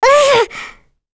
Kirze-Vox_Attack4.wav